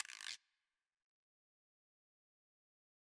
Rare Guiro.wav